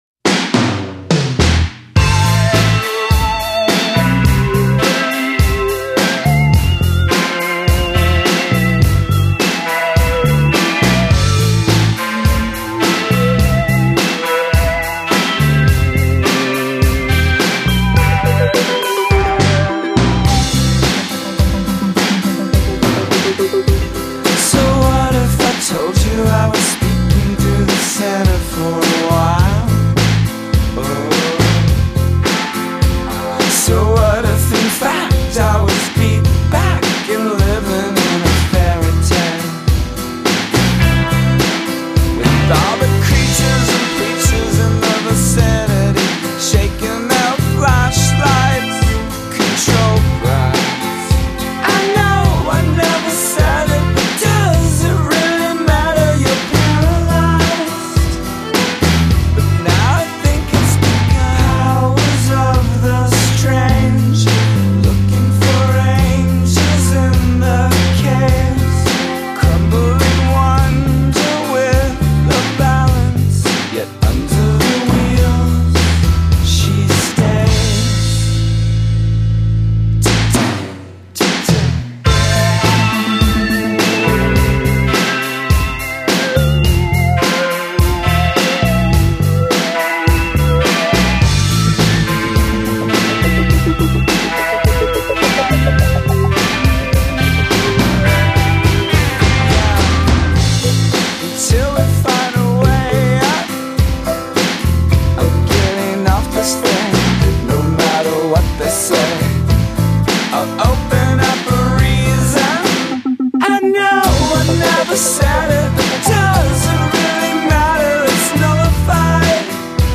durable and consistent hooks